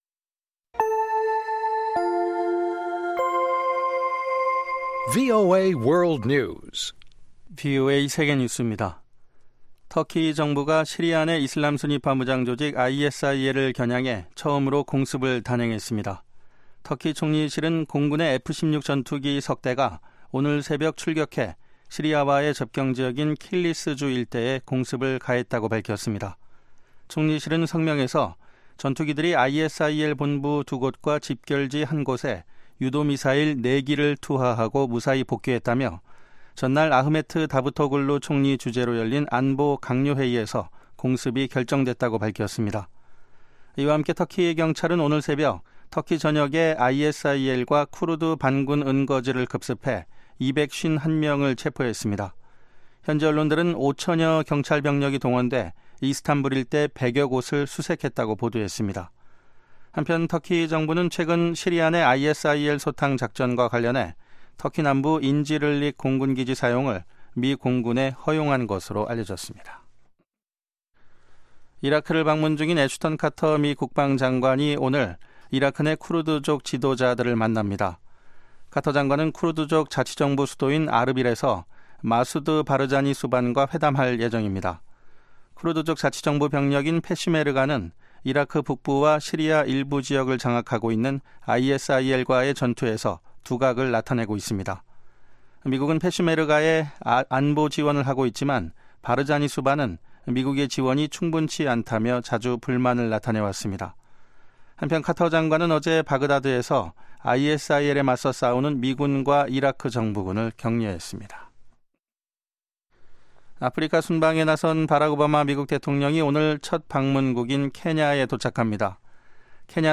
VOA 한국어 방송의 간판 뉴스 프로그램 '뉴스 투데이' 2부입니다.